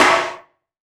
SNARE 037.wav